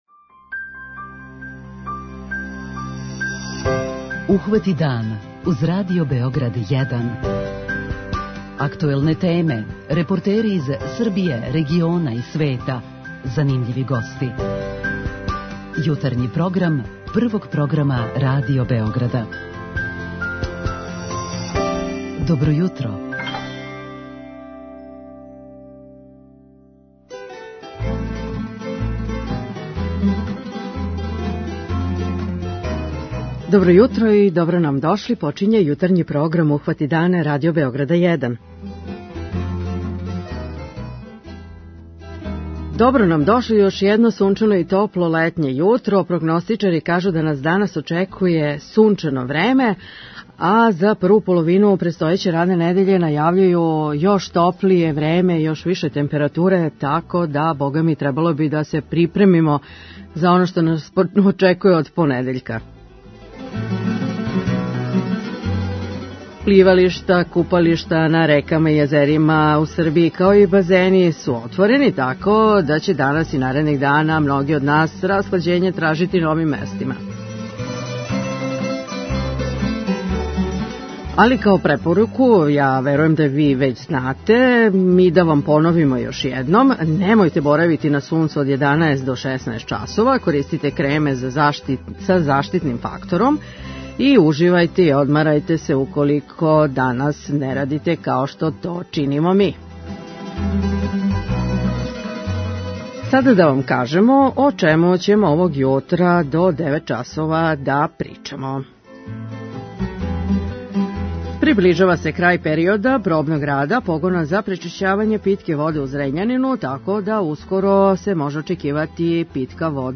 преузми : 29.28 MB Ухвати дан Autor: Група аутора Јутарњи програм Радио Београда 1!